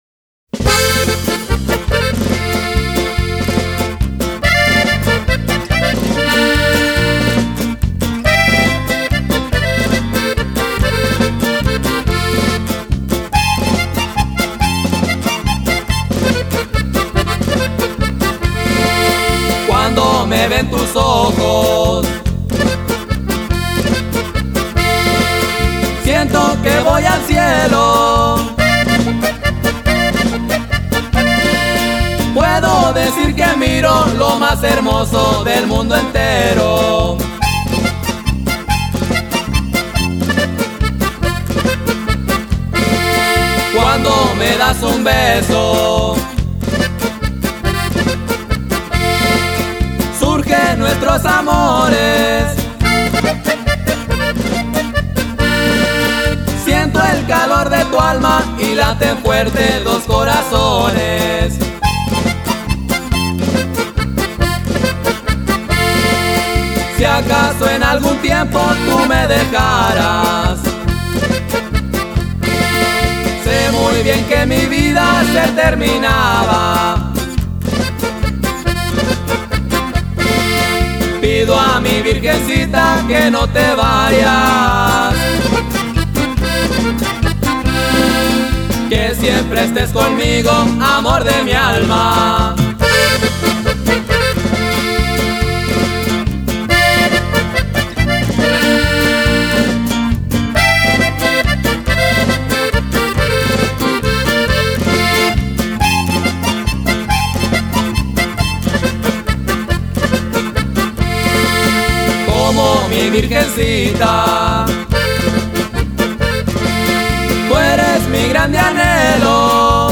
El duo mas reciente del momento
musica norteña